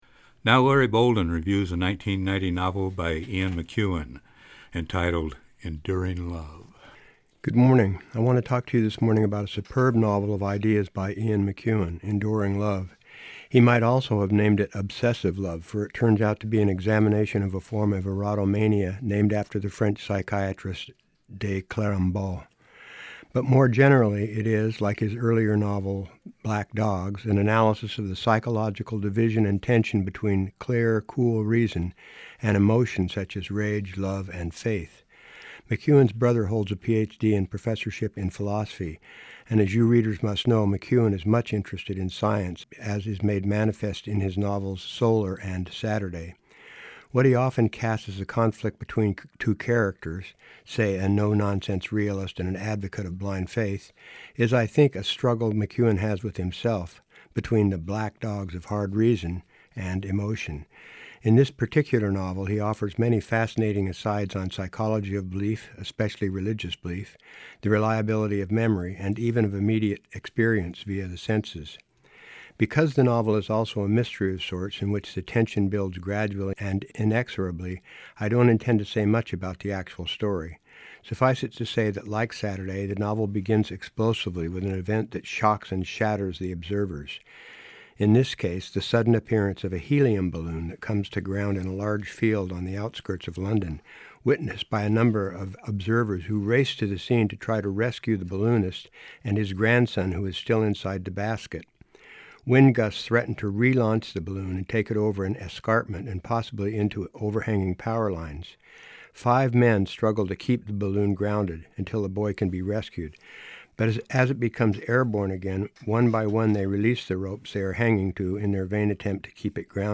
Book Review: "Enduring Love"